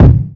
Kick39.wav